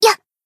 BA_V_Miyako_Swimsuit_Battle_Shout_2.ogg